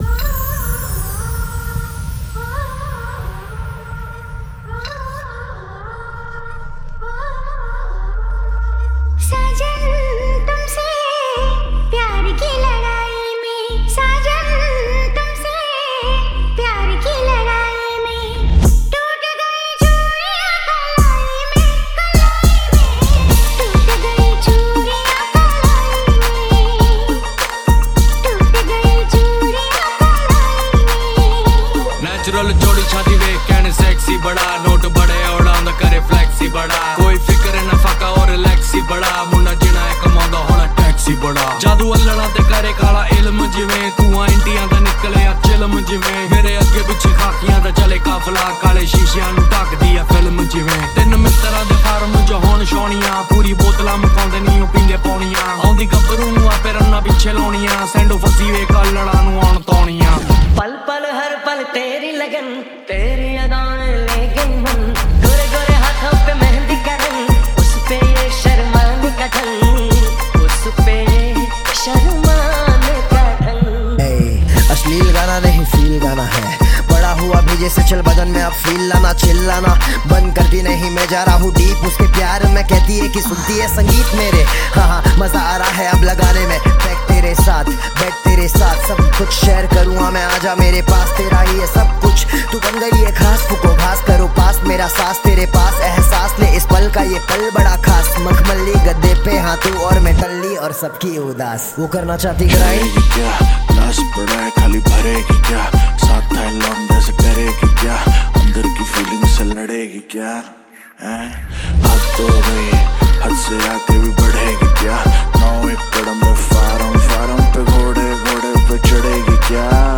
Mashup